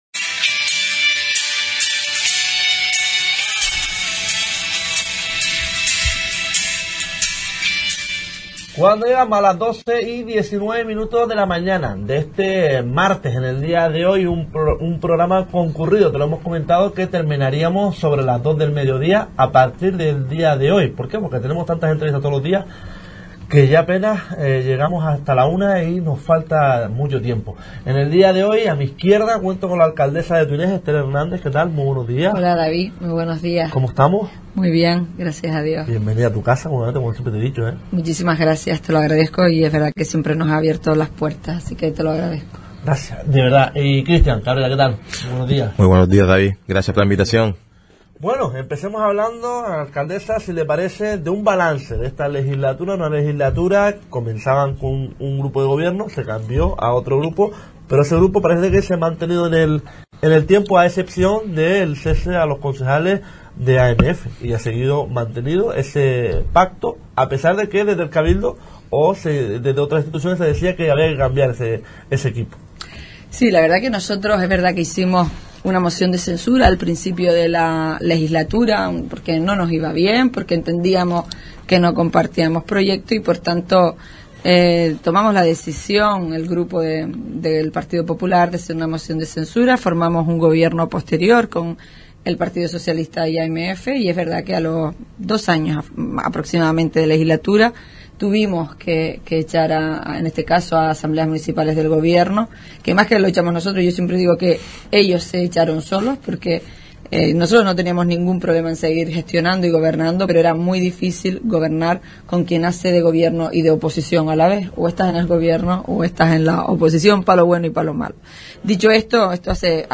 En pasado martes nos visitó la alcaldesa de Tuineje, Esther Hernández y el concejal de Obras y Deportes, Christian Cabrera.